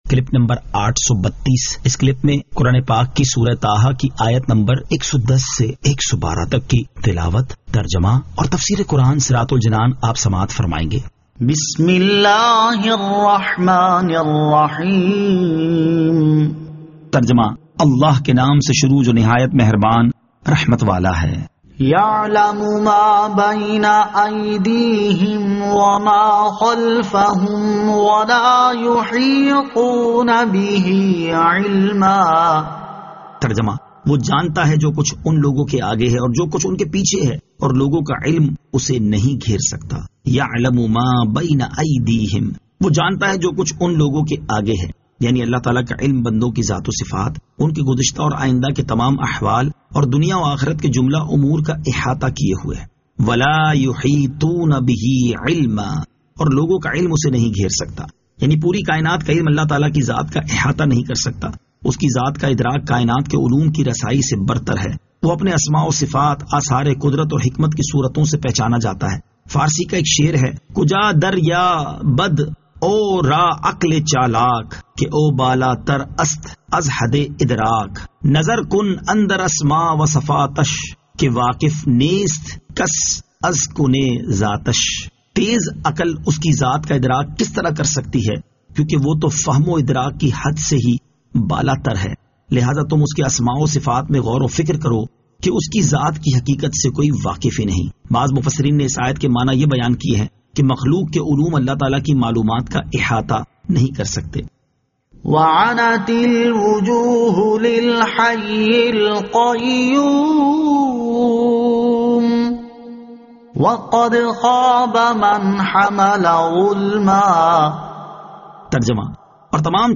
Surah Taha Ayat 110 To 112 Tilawat , Tarjama , Tafseer